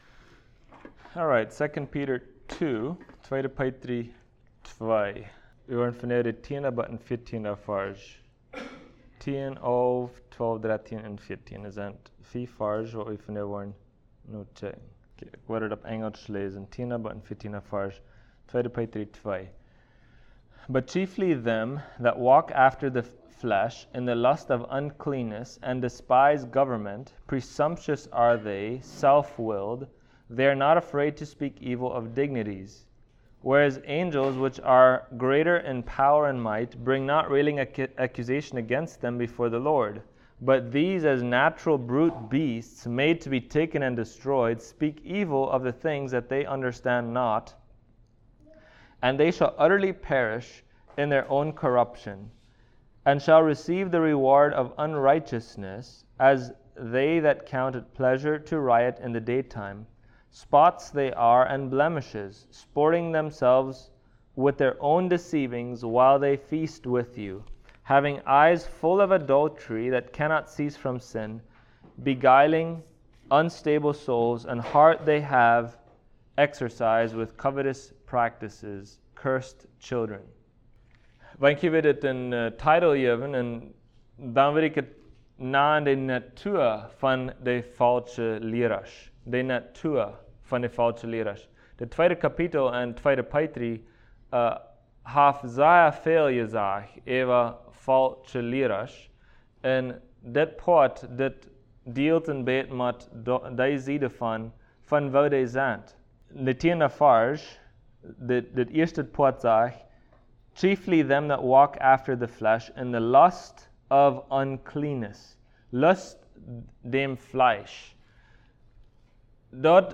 Service Type: Sunday Morning Topics: False Teachers